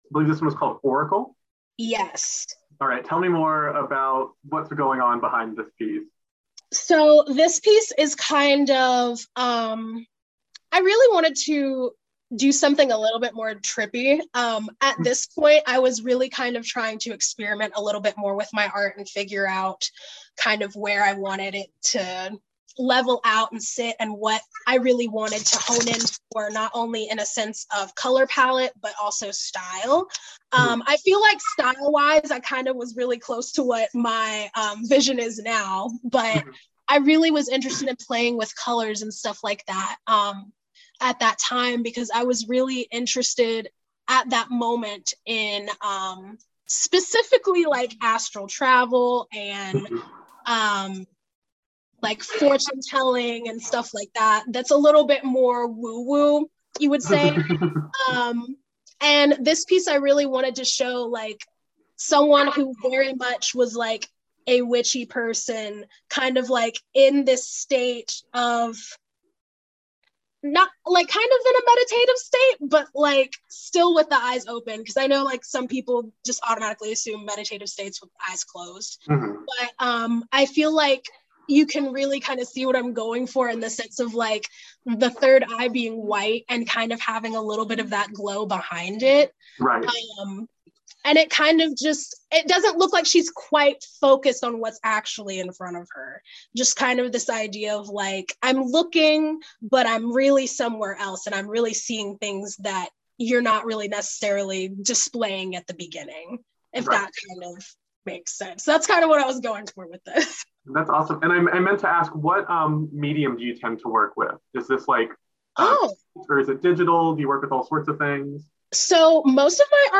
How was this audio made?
over Zoom